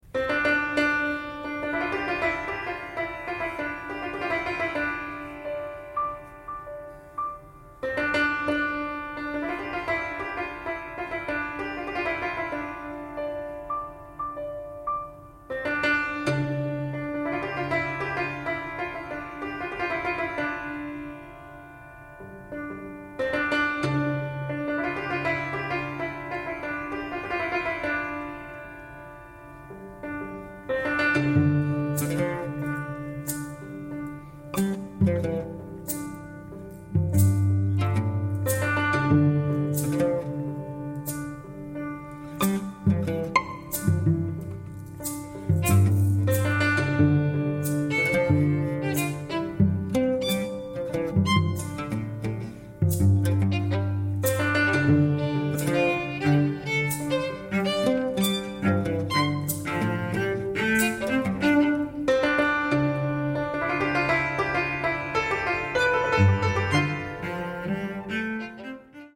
extended piano, oud, violin, cello and percussion
experimental chamber ensemble
Recorded October 1, 2024 at Miller Theatre, NYC